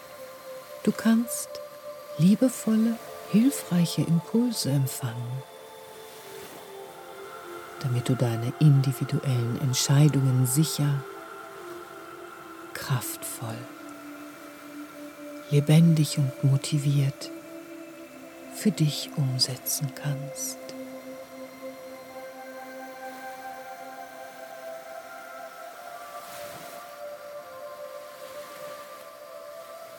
Durch die fein abgestimmte Kombination aus echten Naturklängen und heilsamen sphärischen Melodien erreichen Sie eine Veränderung der wissenschaftlich belegten Schwingungen in Ihrem Gehirn - von Beta-Wellen (38-15 Hz) zu Alpha-Wellen (14-8 Hz) hin zu Theta-Wellen (7-4 Hz).
Für einen optimalen Effekt empfehlen wir das Hören über Kopfhörer.
Ein herausragendes Sounddesign mit beruhigenden Musiken, sphärischen Klängen und faszinierenden dreidimensionalen Naturgeräuschen von den magischsten und heilsamsten Natur-Orten Nordamerikas tragen den Hörer – wie auf akustischen Flügeln – mit in die Entspannung.